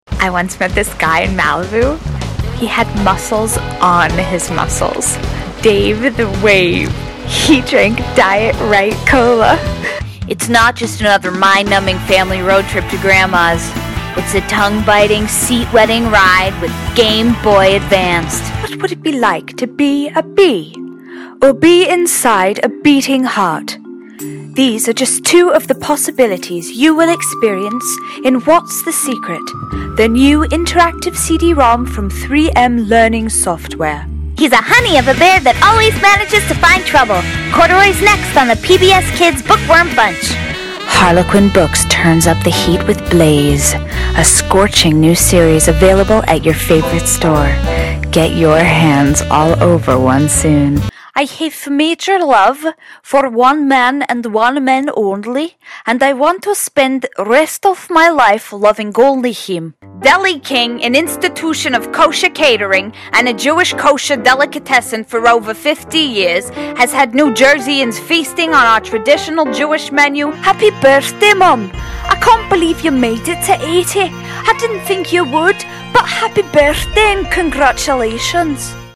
Voice Over Demo